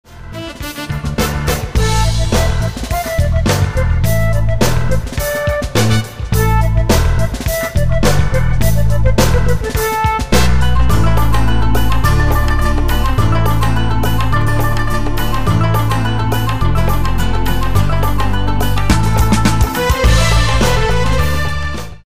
Folk music- instrumental music